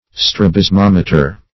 Search Result for " strabismometer" : The Collaborative International Dictionary of English v.0.48: Strabismometer \Stra`bis*mom"e*ter\, n. [Strabismus + -meter.]